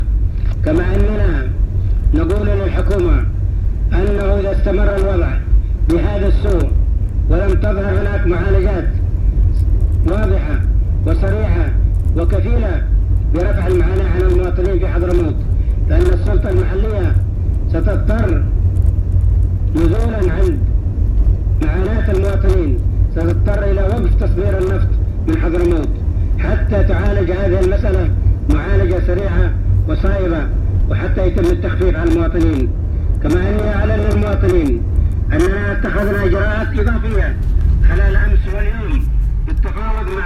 كلمة-محافظ-حضرموت-موجزة.mp3